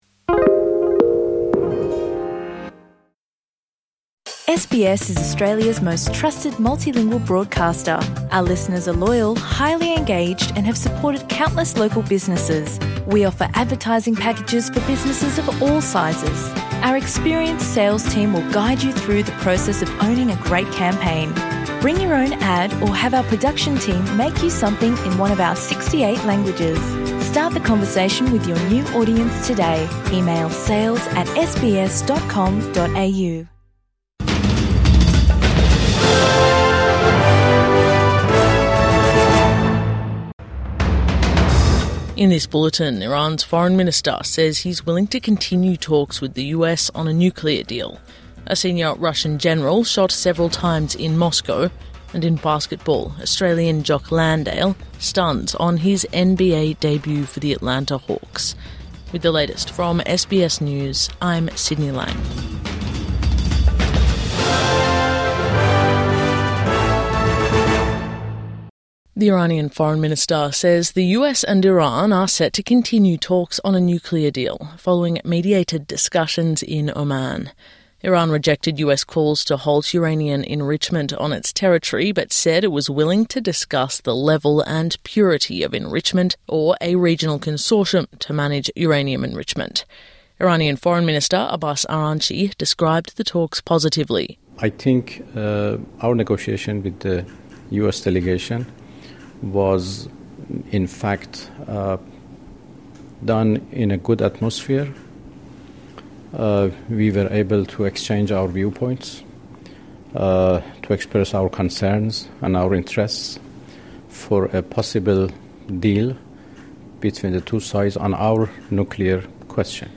Iran US nuclear talks set to continue | Morning News Bulletin 7 February 2026